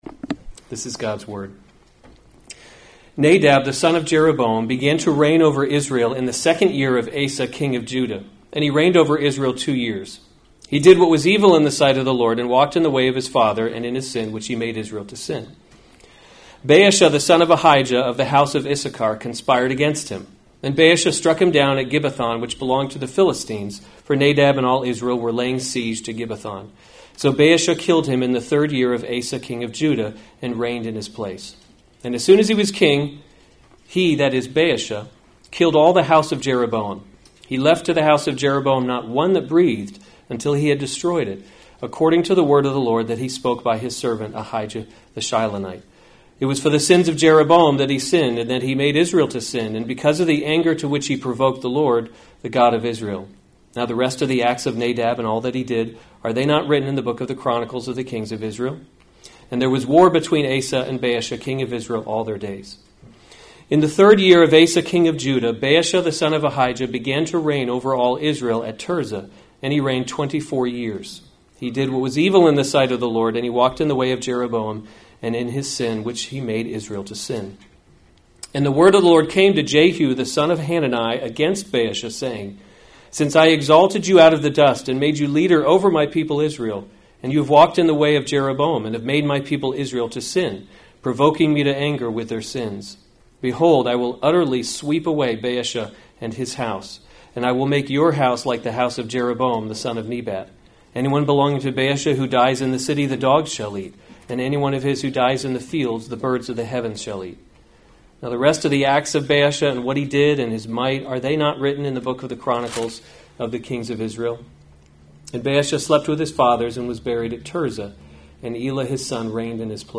March 30, 2019 1 Kings – Leadership in a Broken World series Weekly Sunday Service Save/Download this sermon 1 Kings 15:25 – 16:7 Other sermons from 1 Kings Nadab Reigns […]